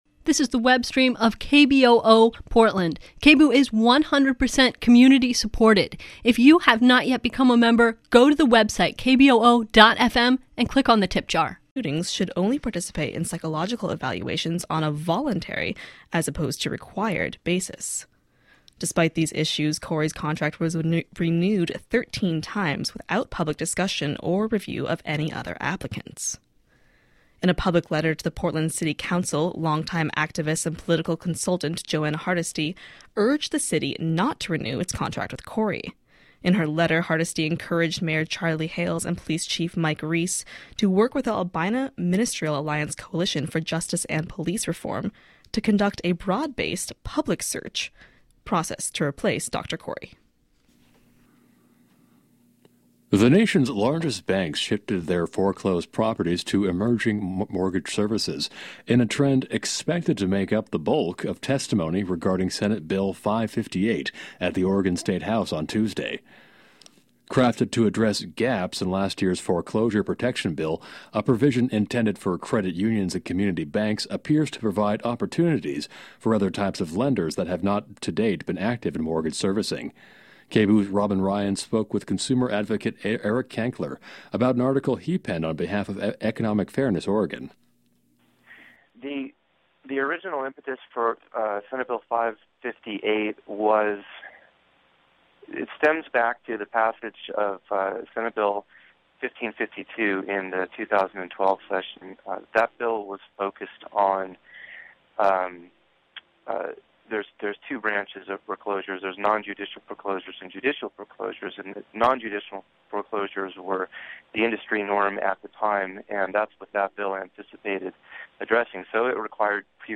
Download audio file Tonight on the KBOO Evening News, we feature a special live interview with Portland City Commissioner Amanda Fritz, on the city council's delay of the decision to move the Right to Dream Too homeless rest area to the Pearl District, under the Broadway Bridge, following c...